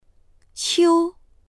qiū 3
qiu1.mp3